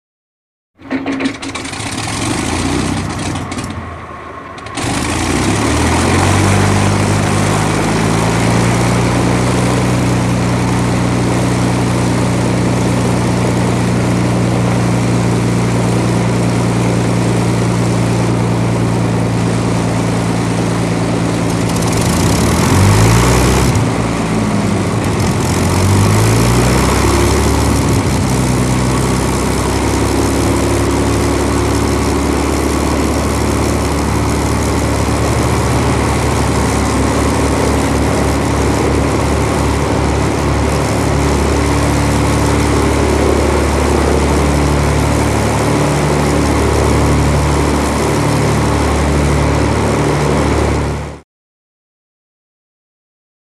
Prop Plane; Start / Idle; Mosquito Prop Aircraft Start Up With Miss Fires, Picks Up To Steady Revs Building Slightly, Then Switch Off And Rundown. Good For Constant In Flight.